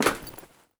dc0f4c9042 Divergent / mods / Soundscape Overhaul / gamedata / sounds / material / human / step / tin3.ogg 28 KiB (Stored with Git LFS) Raw History
tin3.ogg